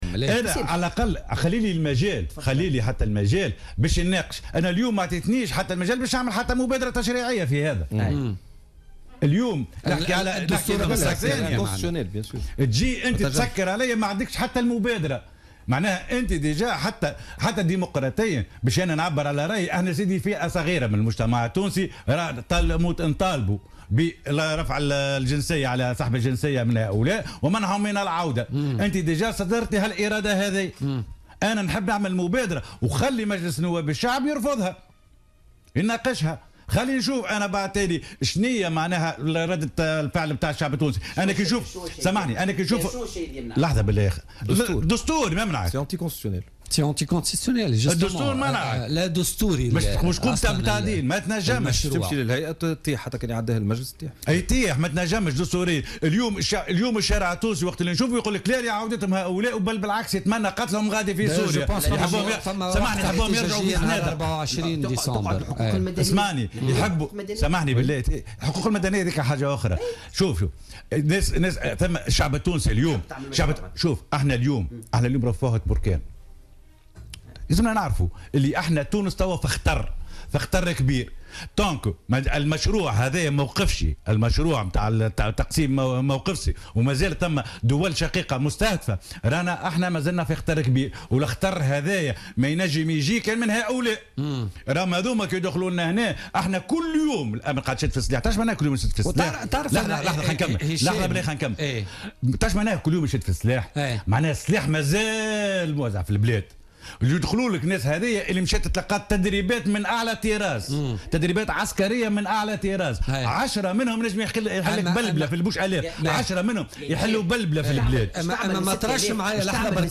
دعا النائب السابق بمجلس نواب الشعب هشام حسني ضيف برنامج بوليتكا لليوم الخميس 15 ديسمبر 2016 إلى سحب الجنسية التونسية من الإرهابيين ومنعهم من العودة إلى تونس.